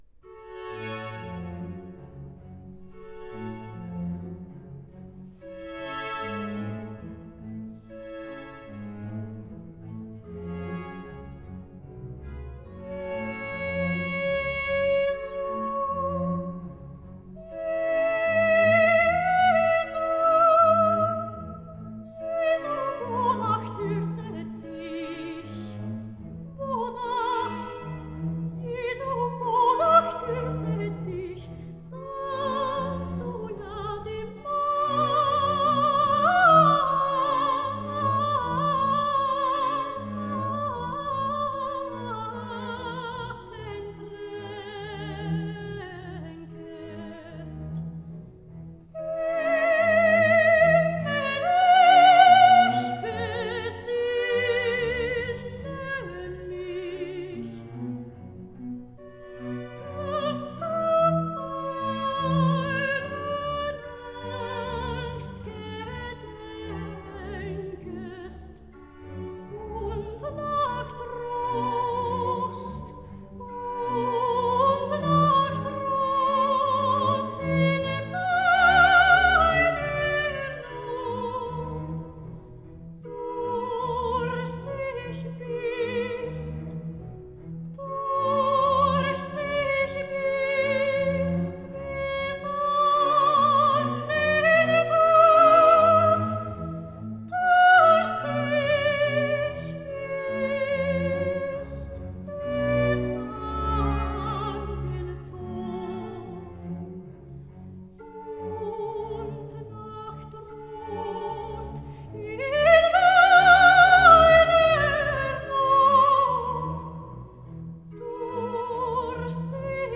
Ana soprano